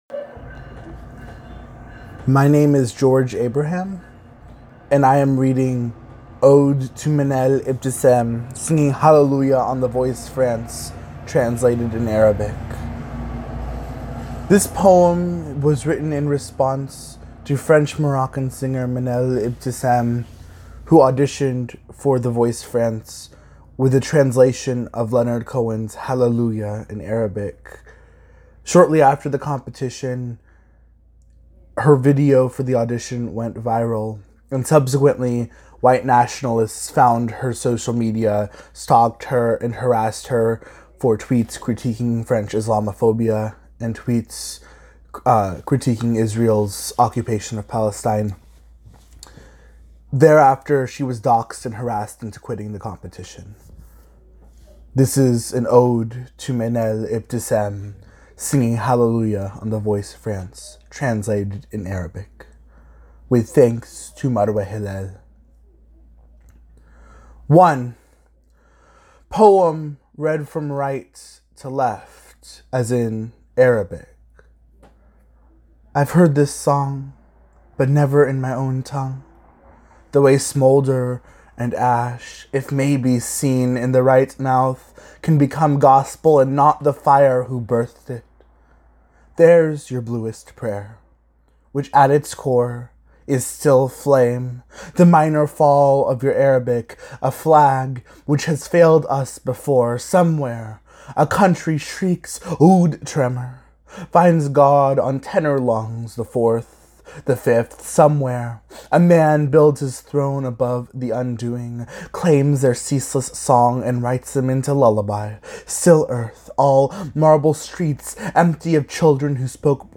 Note: A footnote in Arabic appears for the poem which is intentionally unread in the recording in alignment with the poem's form.